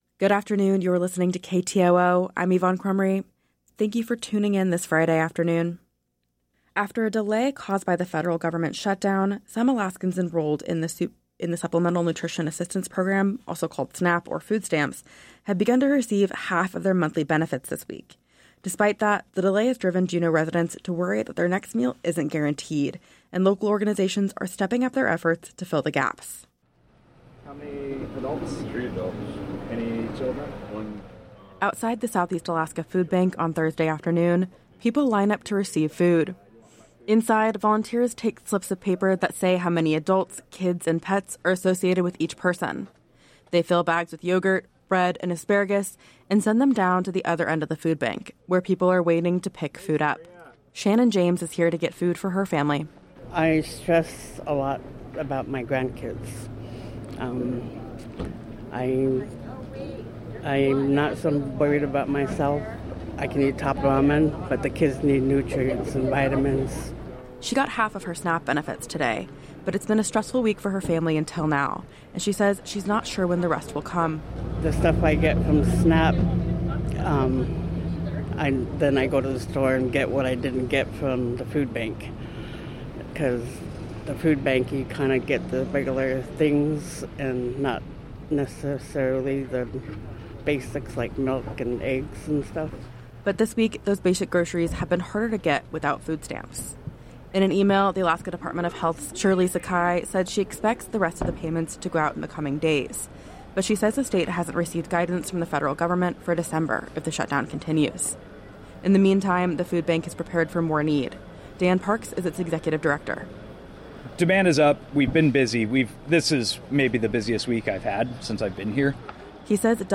Newscast – Friday, Nov. 7, 2025